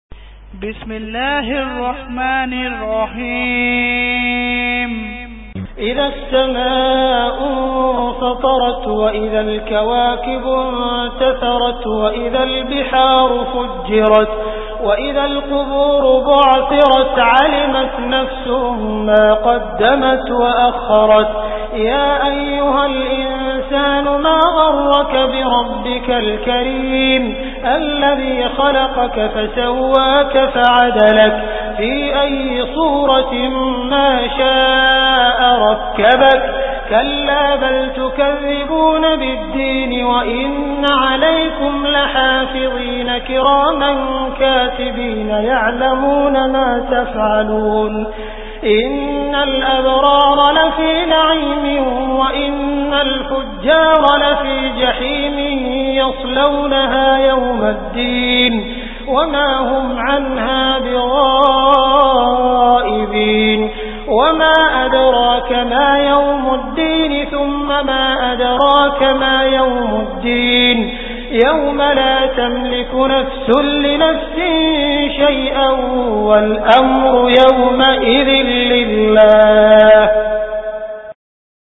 Surah Al Infitar Beautiful Recitation MP3 Download By Abdul Rahman Al Sudais in best audio quality.